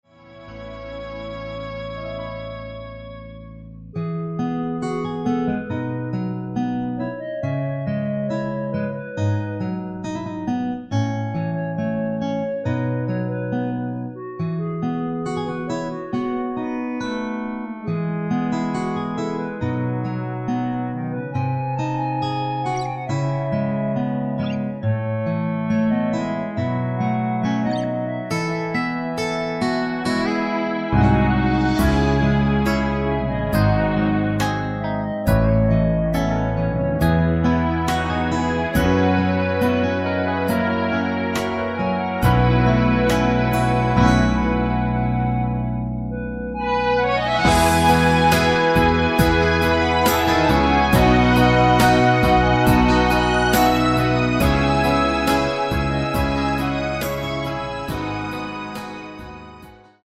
멜로디 MR이라고 합니다.
앞부분30초, 뒷부분30초씩 편집해서 올려 드리고 있습니다.